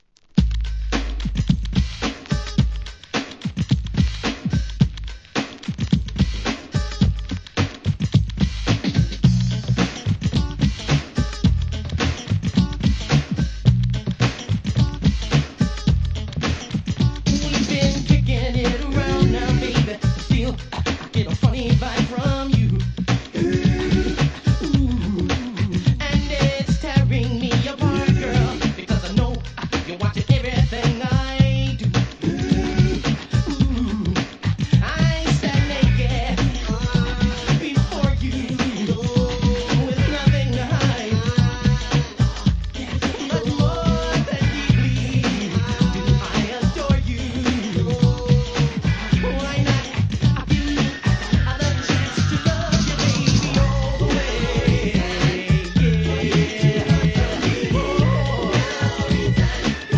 HIP HOP/R&B
NEW JACK SWING!!